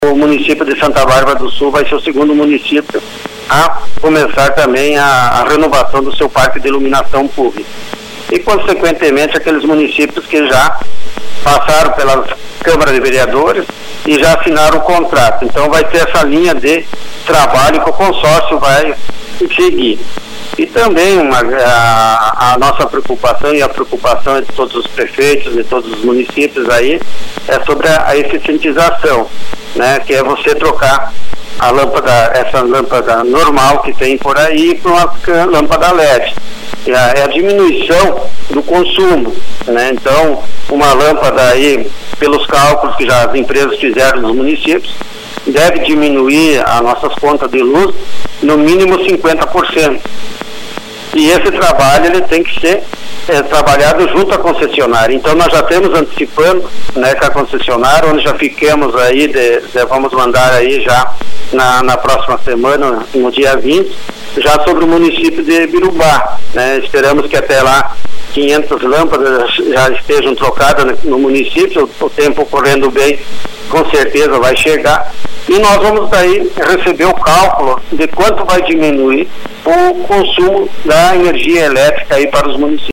O chefe do executivo revelou como estão as tratativas para o videomonitoramento regional via consórcio.